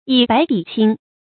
以白詆青 注音： ㄧˇ ㄅㄞˊ ㄉㄧˇ ㄑㄧㄥ 讀音讀法： 意思解釋： 以白色詆毀青色。